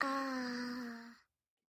Звук умиления ребенка аххх